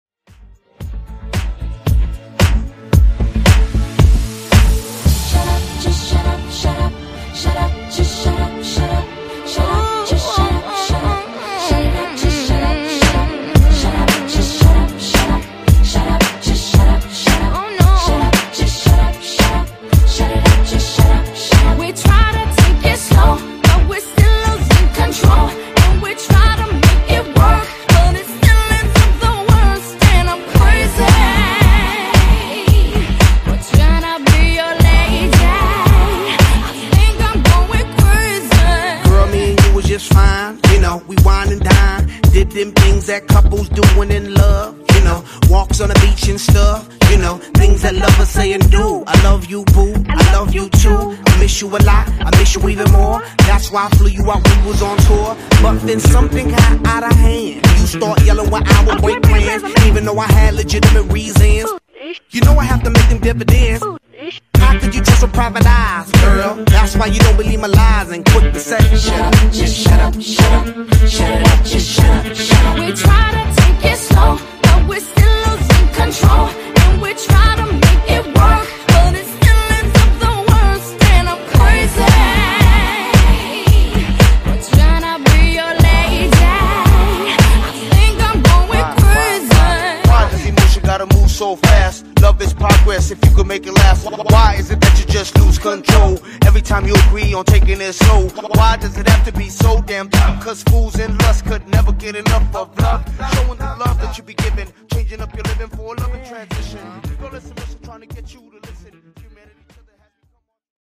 Genre: RE-DRUM
Clean BPM: 112 Time